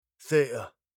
コックニーは、「think」「theatre」「author」などの無声の「th」/θ/を、/f/と発音します。
• theatre（劇場）：θɪə.tə → fɪəʔə
🇬🇧発音：theatre（劇場）